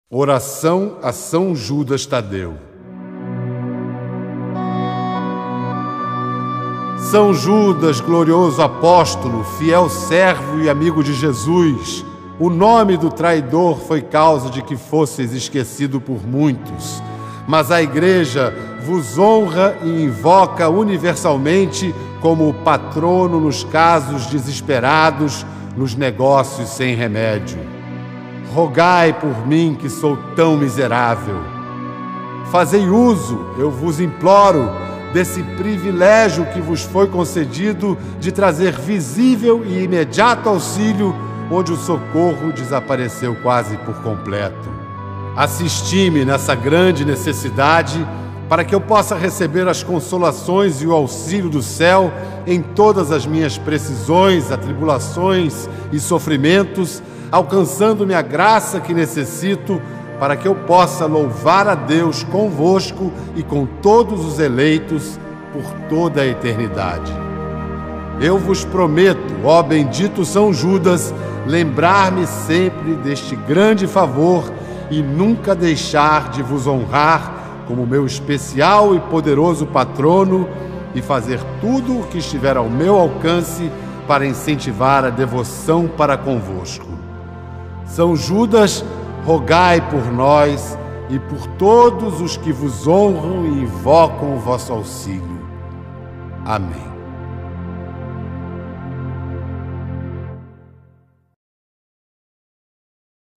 Voz: Pedro Bial